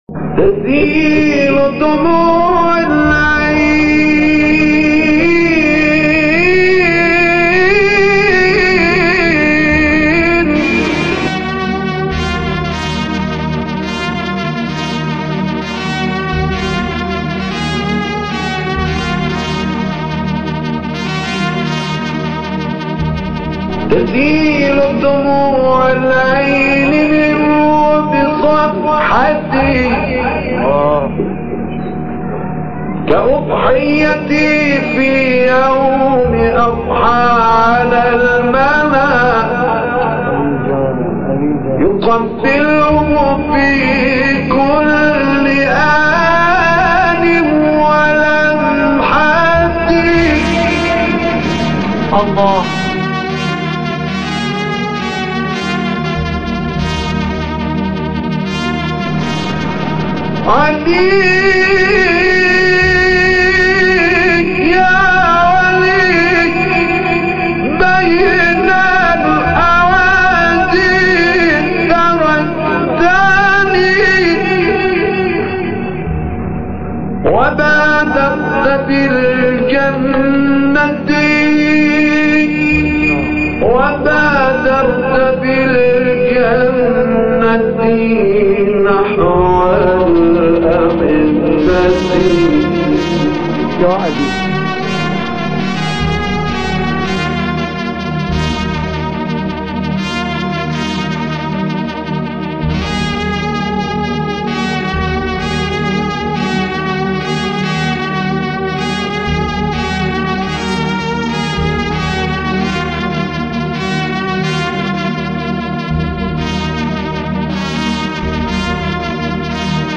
از طرفی ایشان بسیار شوخ و خوش‌صدا هم بود و جنس صدایشان همان جنس صدای مداح‌های آذری‌زبان بود.
صدای گرمی هم داشت و همین موجب شده بود تا در زمینه ابتهال بتواند فعالیت بیشتری داشته باشد.